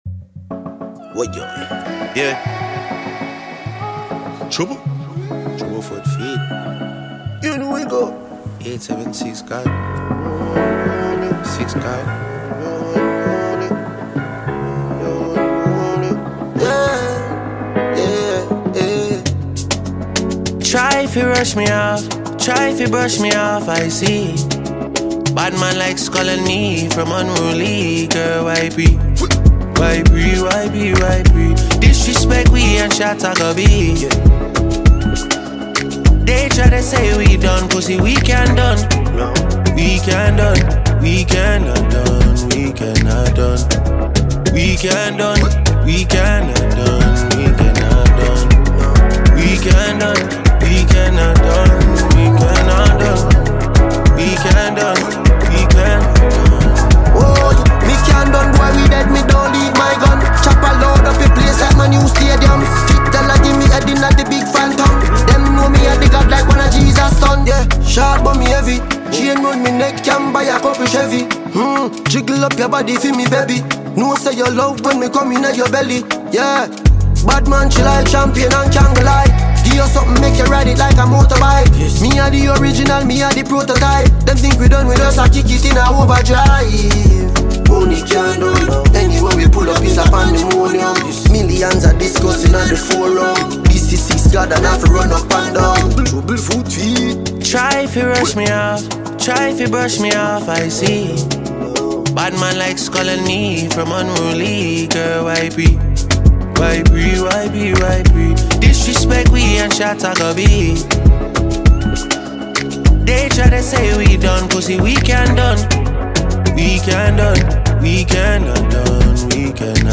Multiple award-winning Jamaican reggae-dancehall musician